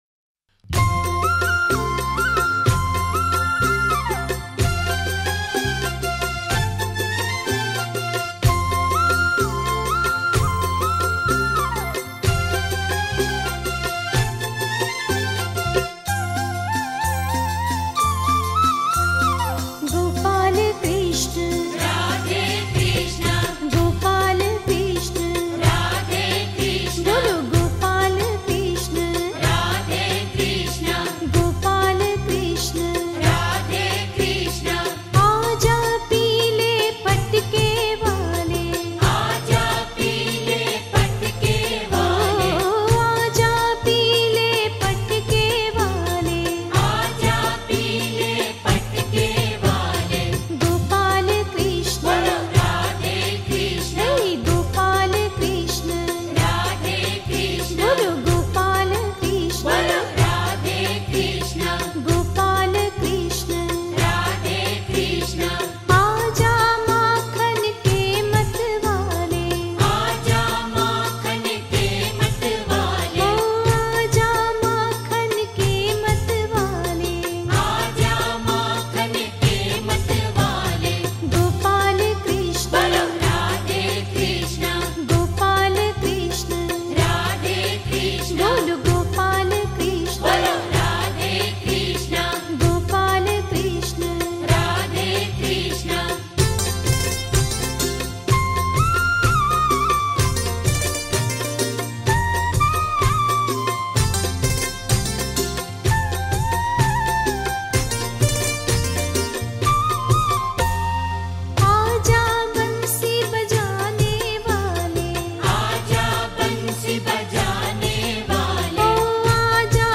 Gujarati Bhajan